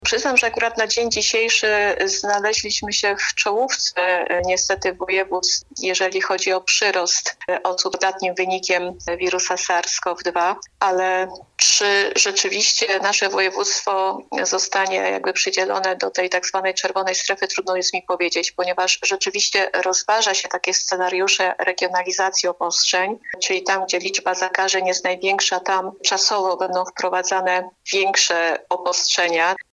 O tym oraz o akcji szczepień nauczycieli rozmawiali goście programu RZG Interwencje.